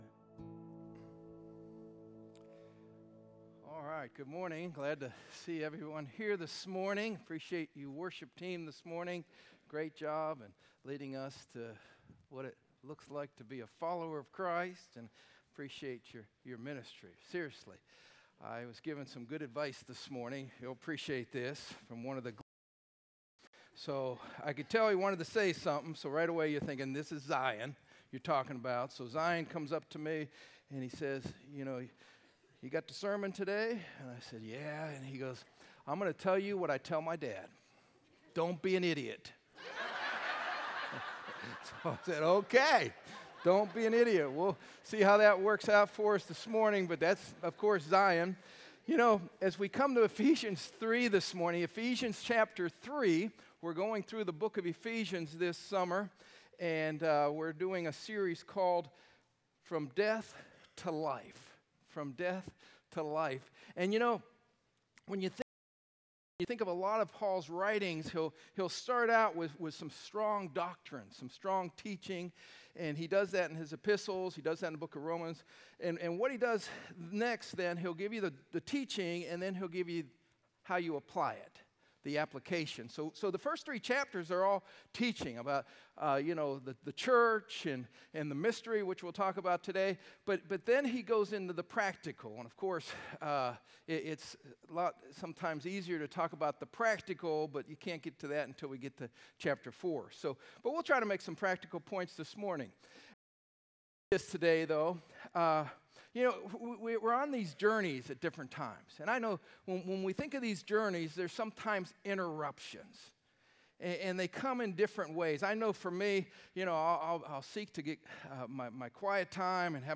6-1-25-sermon-audio.m4a